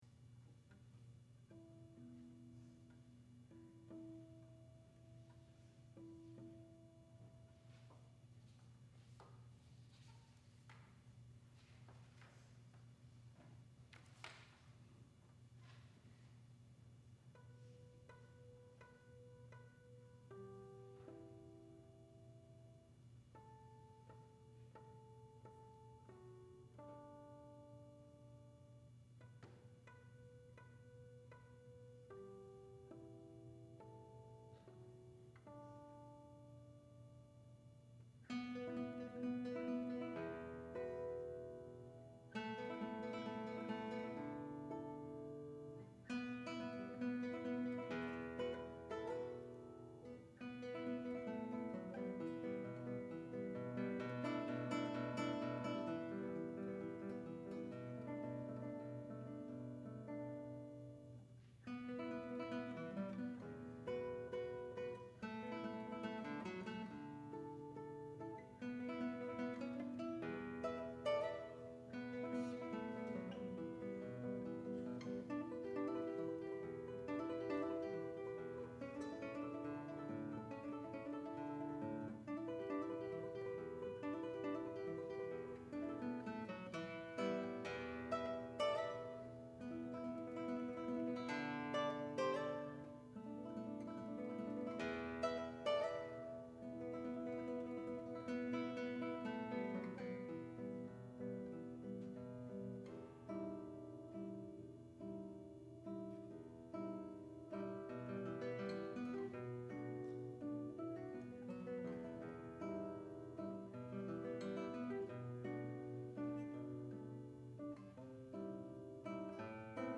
for Guitar (2006)